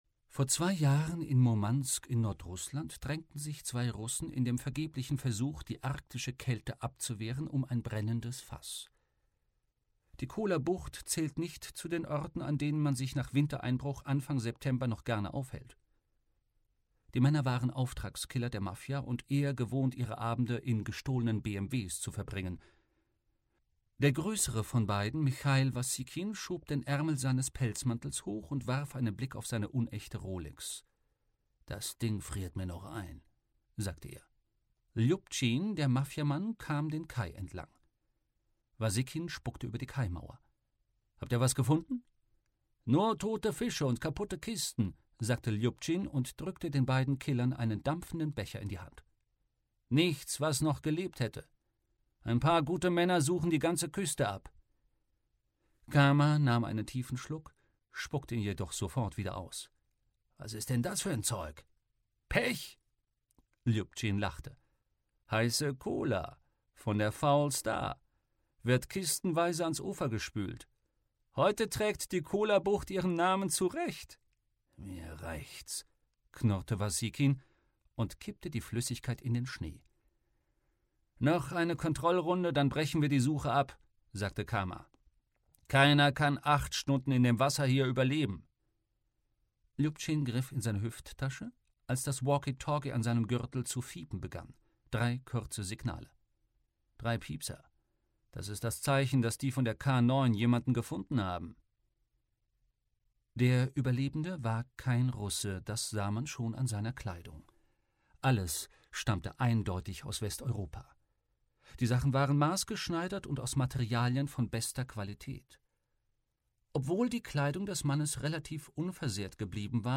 Artemis Fowl - Die Verschwörung (Ein Artemis-Fowl-Roman 2) - Eoin Colfer - Hörbuch